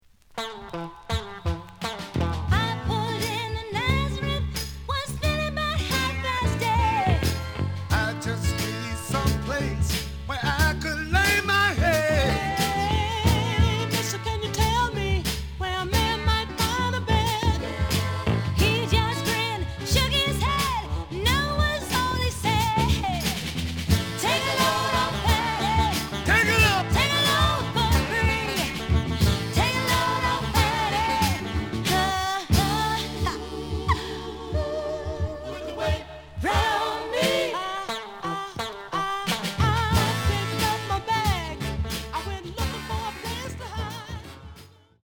The listen sample is recorded from the actual item.
●Genre: Soul, 60's Soul